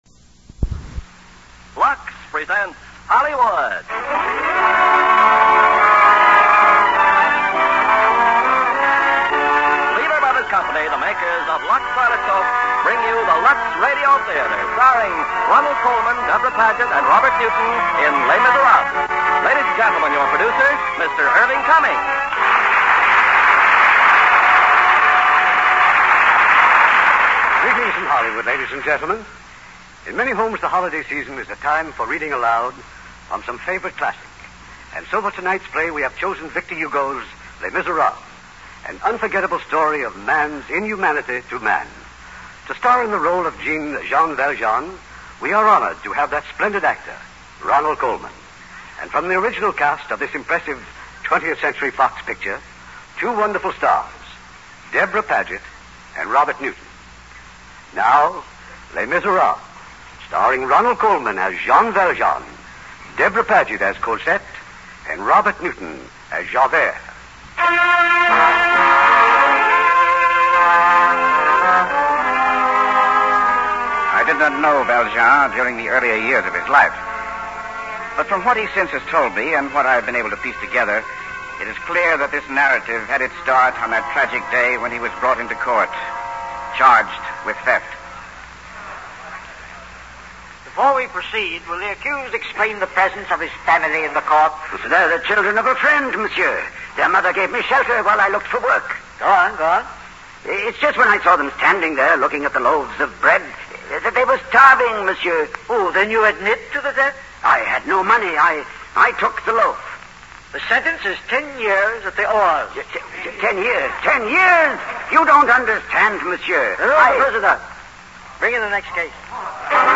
Les Miserables radio theater
The following is a 7 episode radio theater production with Orson Welles:
Les-Miserables-Lux-theater.mp3